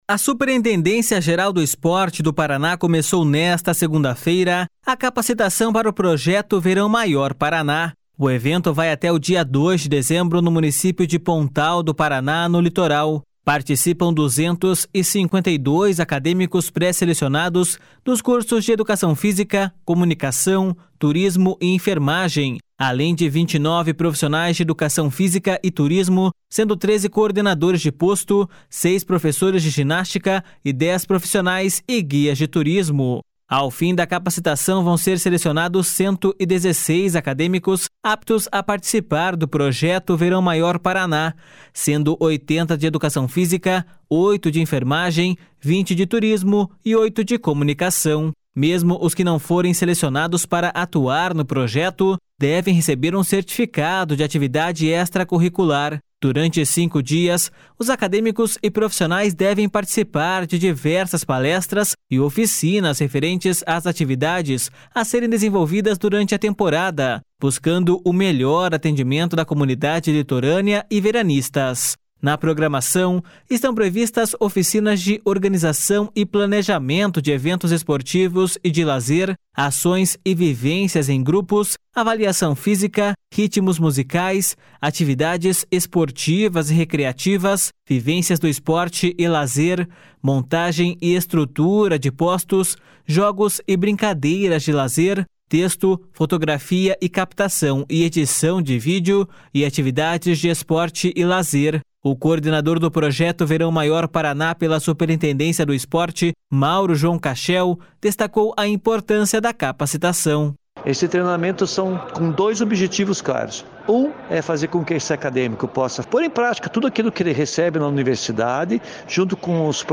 Segundo o diretor-presidente da Paraná Turismo, Irapuan Cortes, a edição 2022/2023 será uma das maiores do projeto do governo estadual que promove diversas ações no verão.// SONORA IRAPUAN CORTES.//